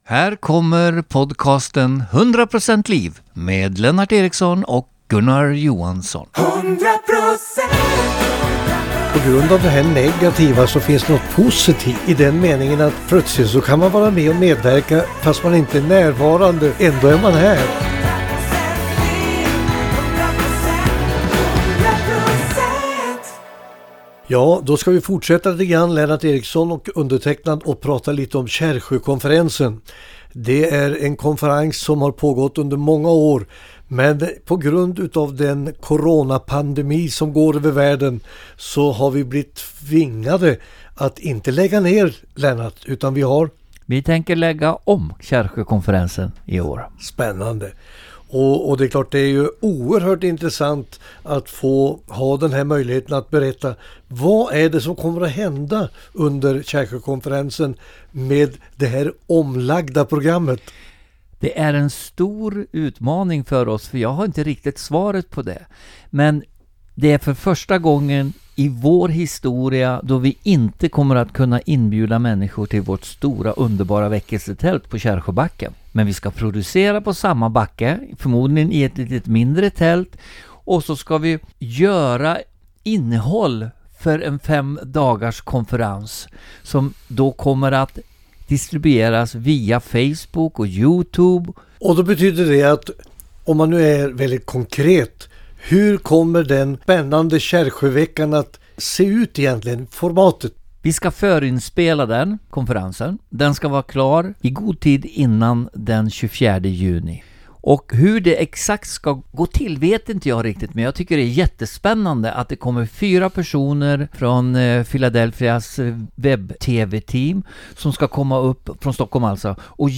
Samtalet